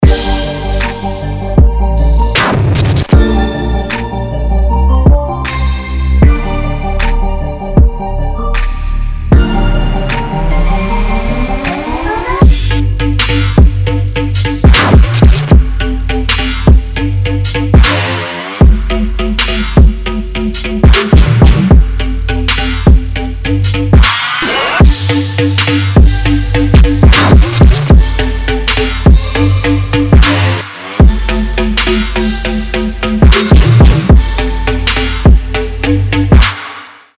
атмосферные
Electronic
EDM
без слов
Trap
future bass